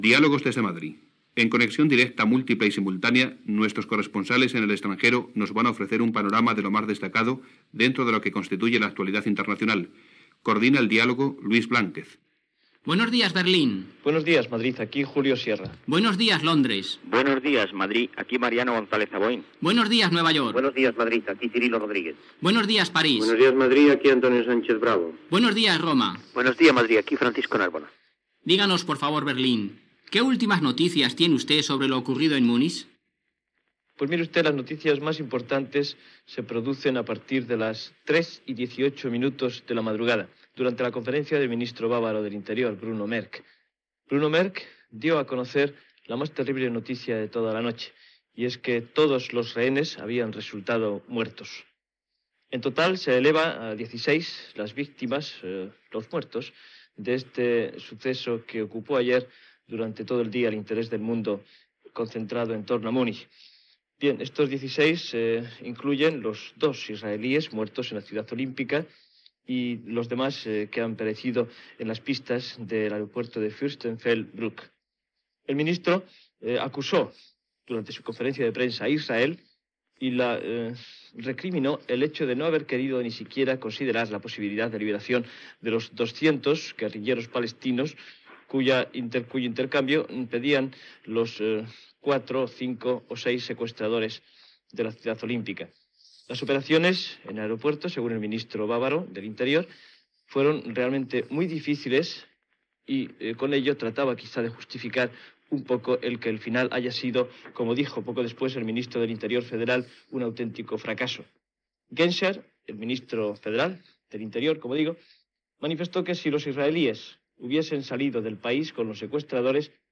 Ressó de la premsa britànica.
Informatiu